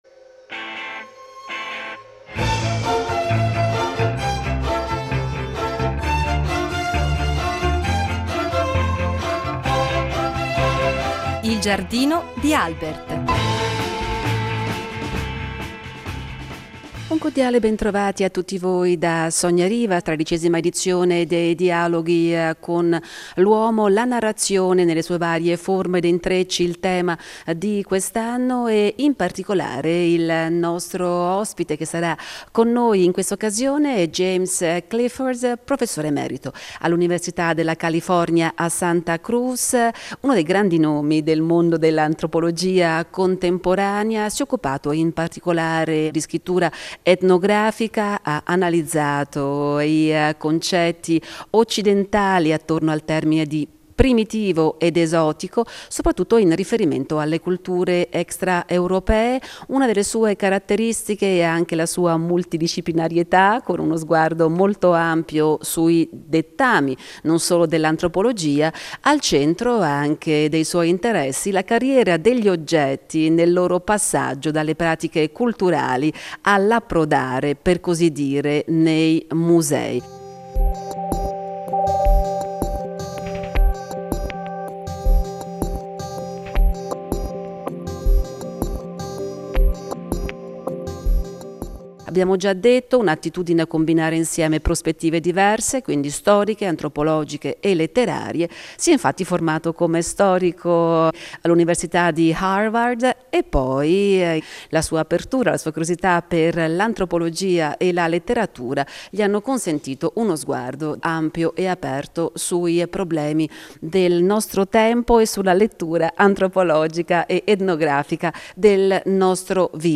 L’abbiamo incontrato in occasione della tredicesima edizione dei Dialoghi con l’Uomo di Pistoia, che aveva come tema la vita nei suoi intrecci di storie e immaginari. Con lui abbiamo conversato di dove stia andando l’antropologia oggi, del ruolo dell’antropologo nella società odierna, ma anche dell’antropologia socioculturale e dell’idea relativistica di cultura.